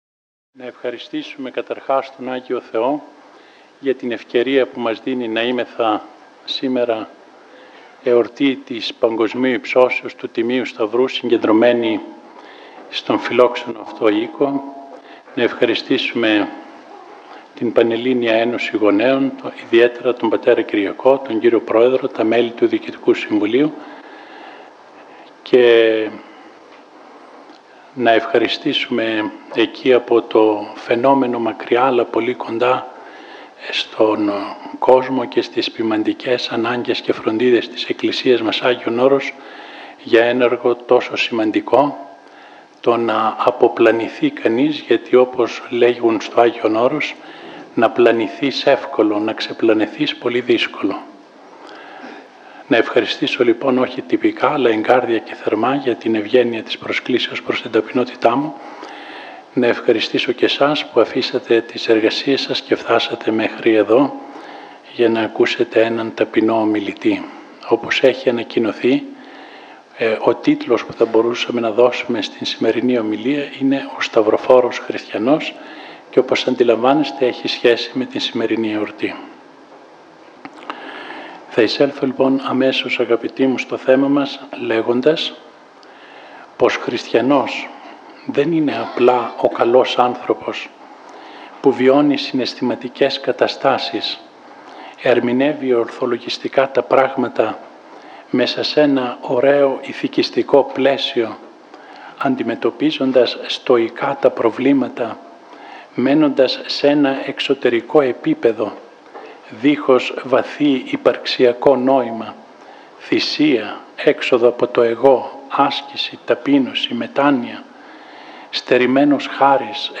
Σεμινάρια Ορθοδόξου Πίστεως. Ενορία Αγίας Παρασκευής Αττικής.